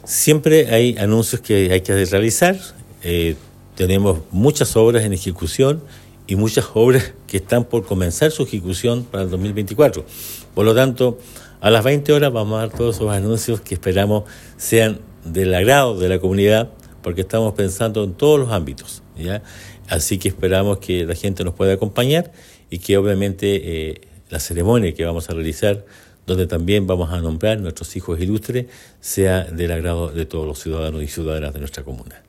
El Alcalde Emeterio Carrillo, reiteró la invitación a la comunidad osornina tanto del sector urbano como rural, a participar de esta actividad y asi conocer de primera mano las distintas iniciativas para la comuna.